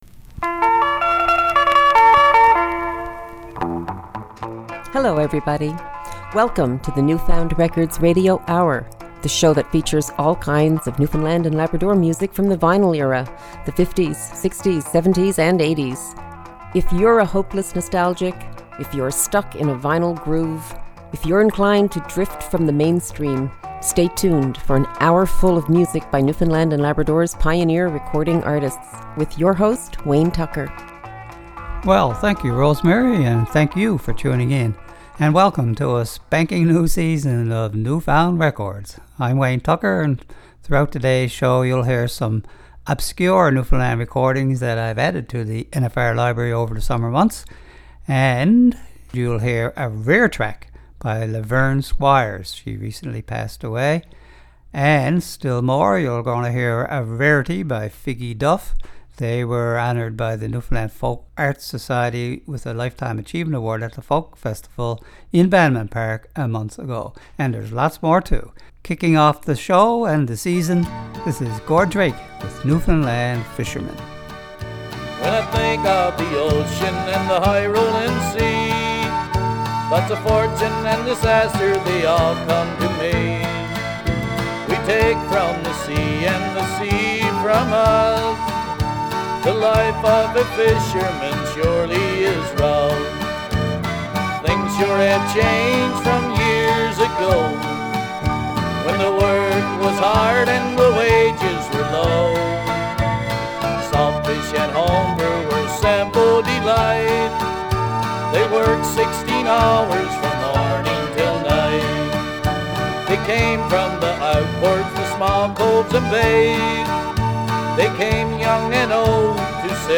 Recorded at CHMR studios, MUN, to air Sep. 10. 2016.